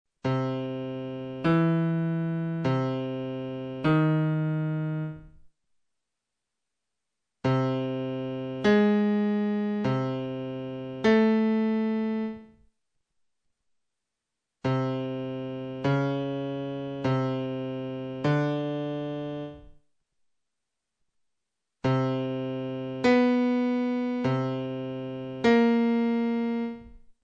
L'ascolto propone una successione di intervalli maggiori e minori, presentati in coppia.